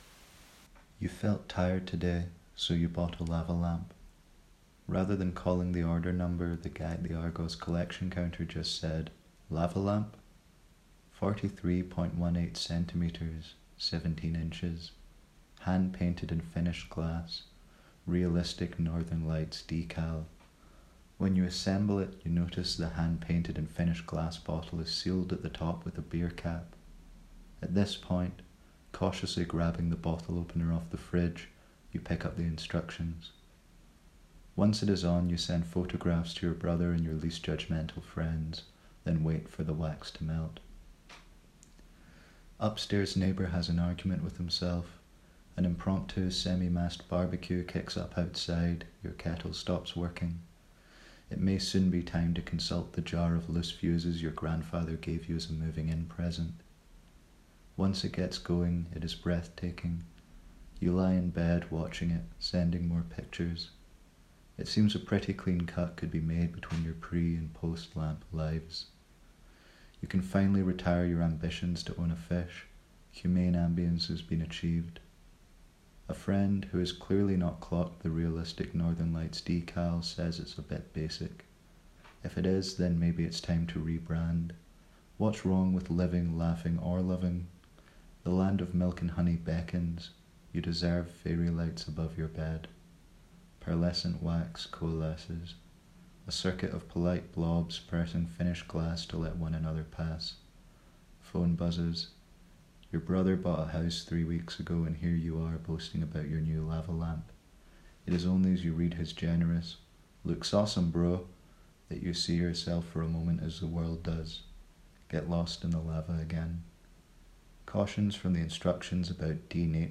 leer un extracto de lampobsesión